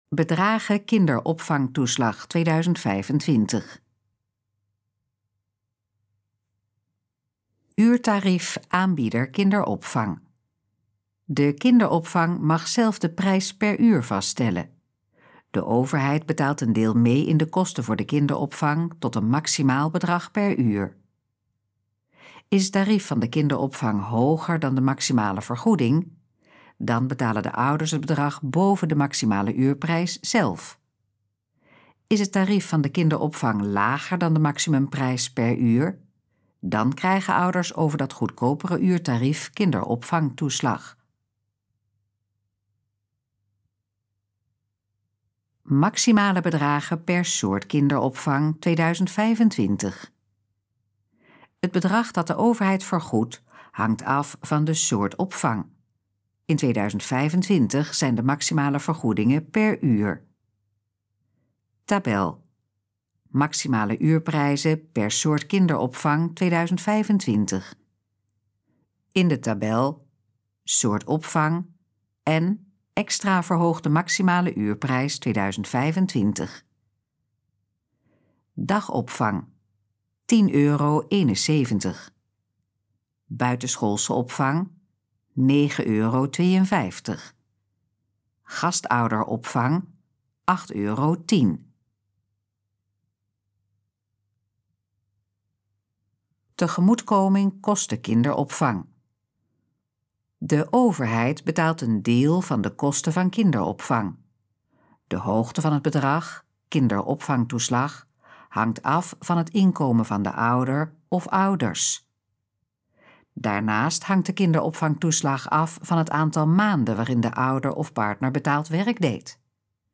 Gesproken versie van: Bedragen kinderopvangtoeslag 2025
Dit geluidsfragment is de gesproken versie van de pagina Bedragen kinderopvangtoeslag 2025.